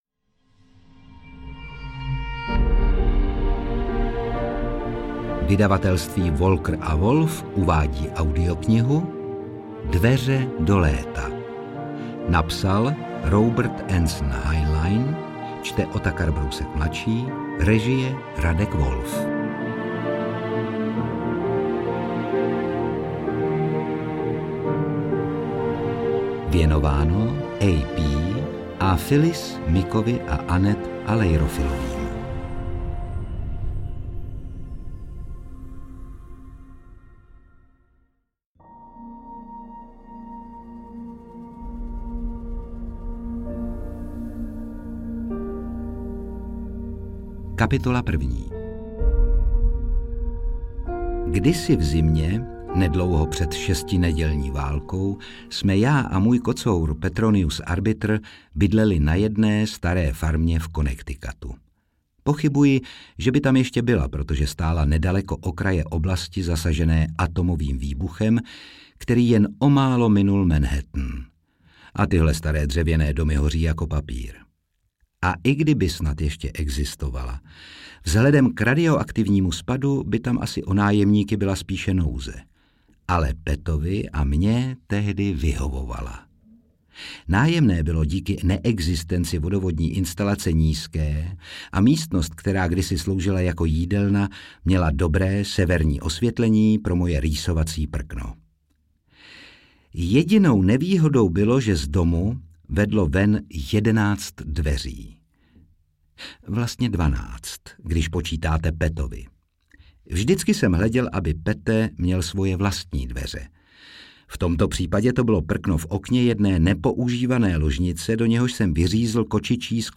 Interpret:  Otakar Brousek ml.
AudioKniha ke stažení, 12 x mp3, délka 8 hod. 43 min., velikost 478,9 MB, česky